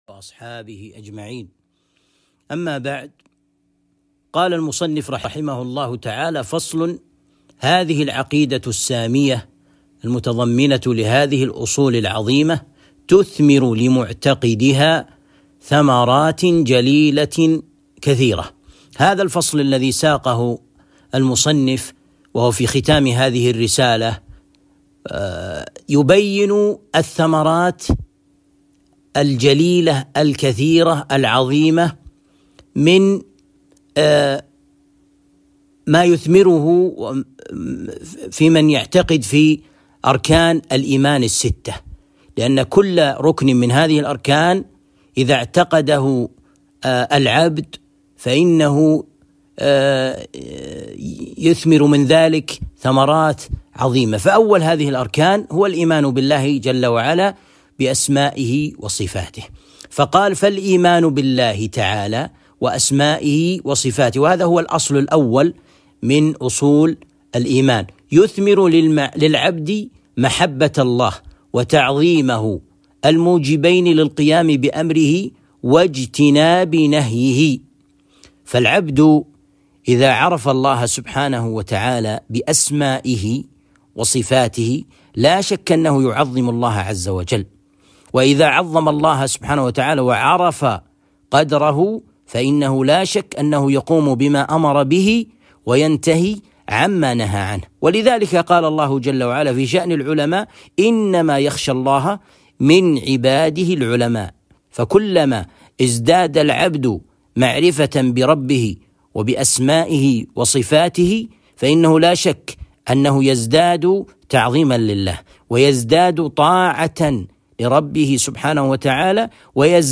الدرس الثامن - شرح عقيدة اهل السنة والجماعة - الشيخ ابن عثيمين